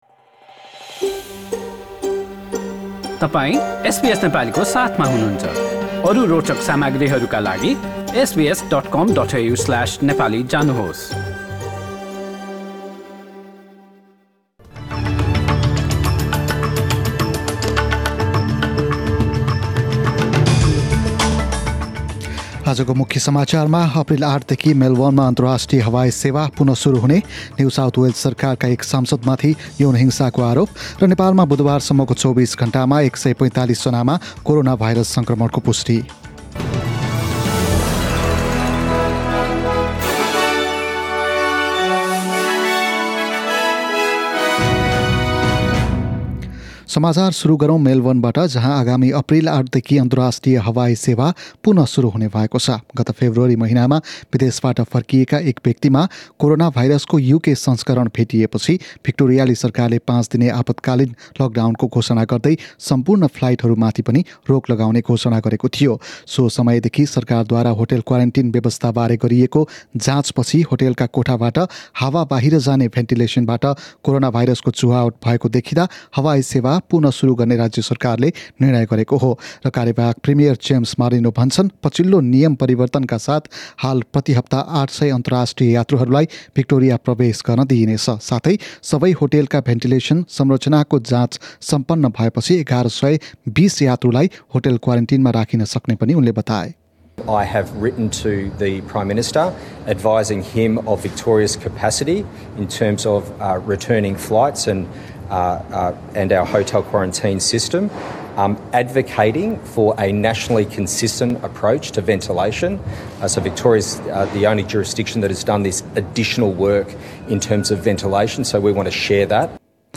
Listen to latest news headlines from Australia in Nepali.